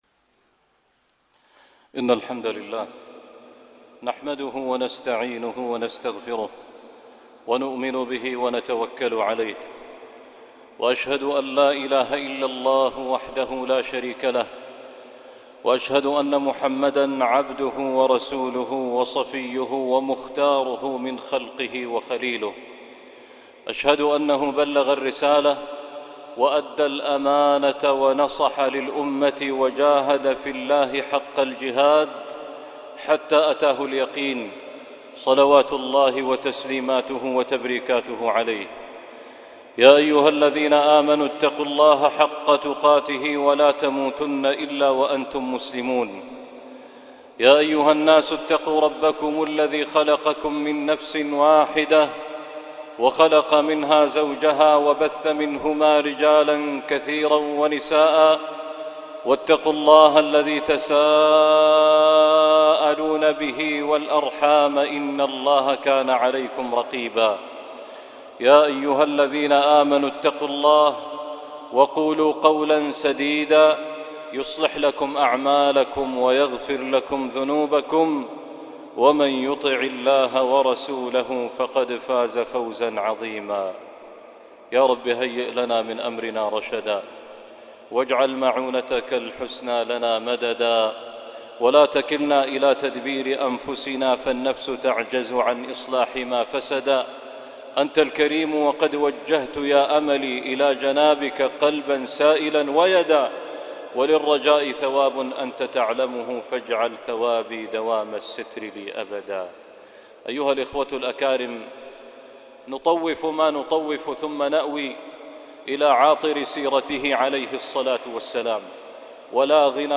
مع أنفاس المصطفىﷺ - خطبة الجمعة